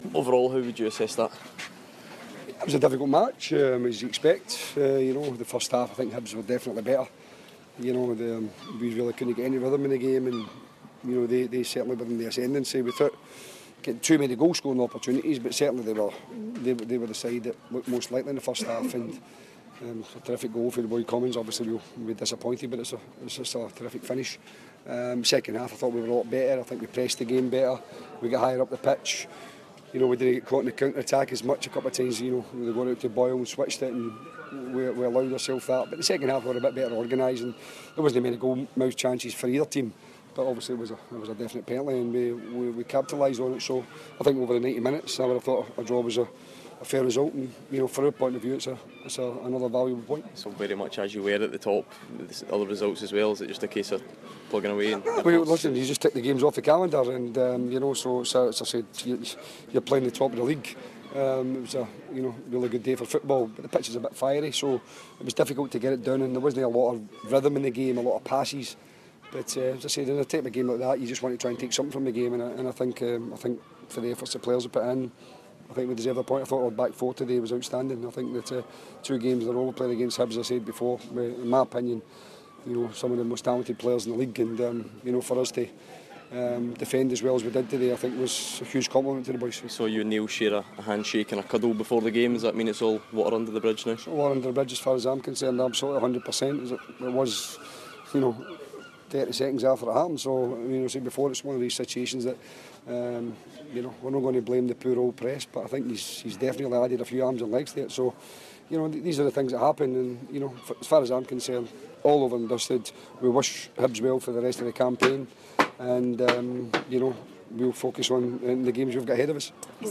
Neil Lennon & Jim Duffy Post Match - 08/04/16